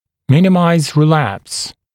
[‘mɪnɪmaɪz rɪ’læps][‘минимайз ри’лэпс]минимизировать рецидив